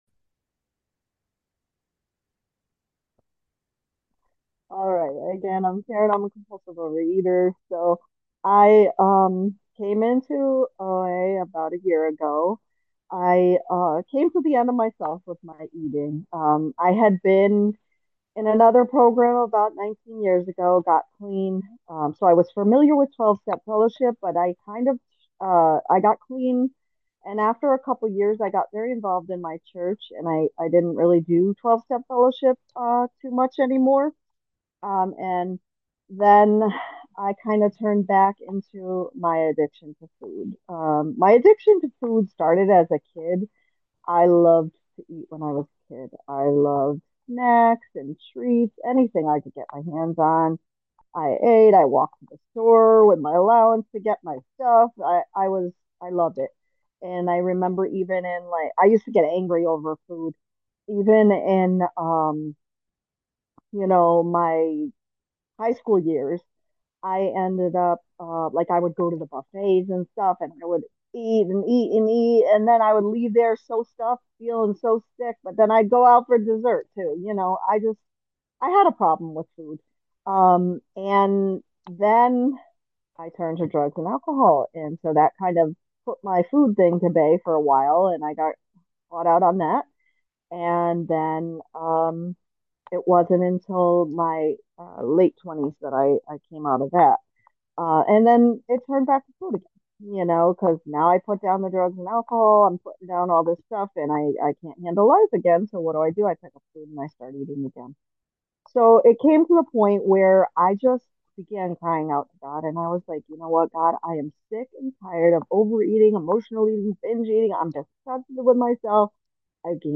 2025-12-27: Unity Group Newcomer Meeting SAT 11PM ET – OA Foot Steps